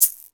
Shaken Shaker 04.wav